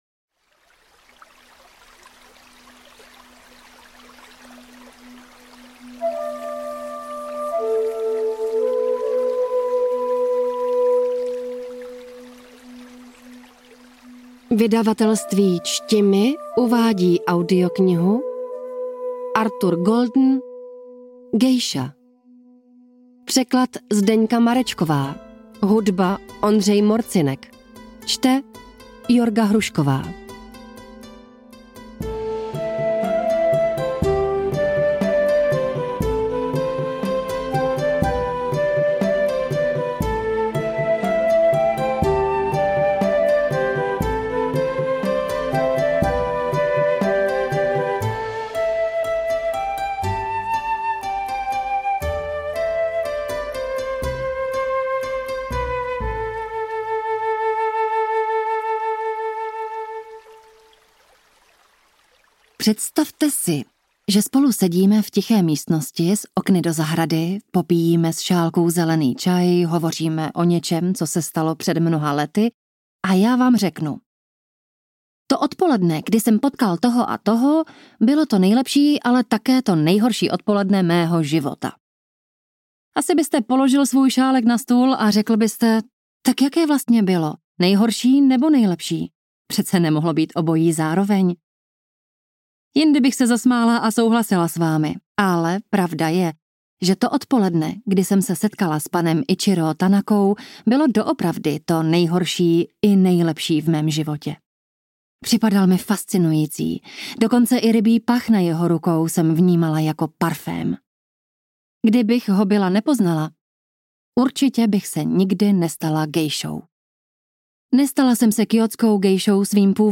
Kategorie: Román, Historické
Gejsa_ukazka.mp3